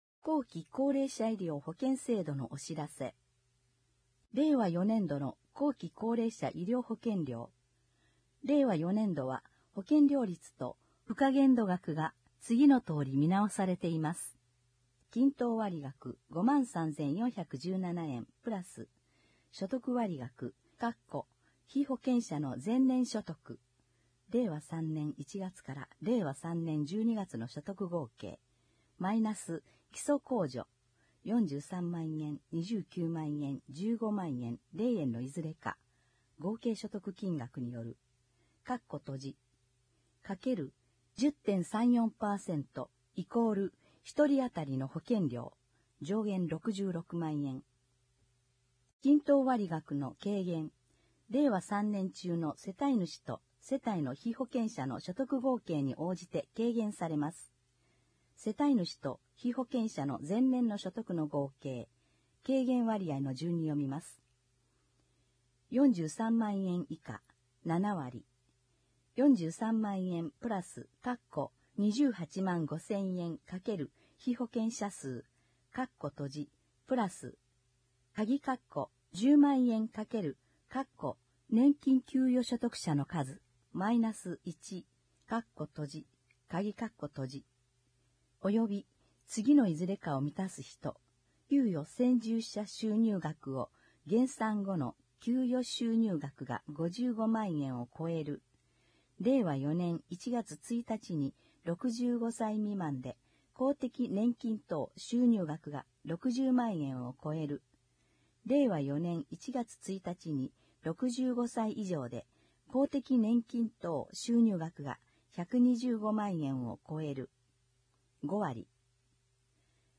音訳広報